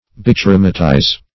Search Result for " bichromatize" : The Collaborative International Dictionary of English v.0.48: Bichromatize \Bi*chro"ma*tize\, v. t. To combine or treat with a bichromate, esp. with bichromate of potassium; as, bichromatized gelatine.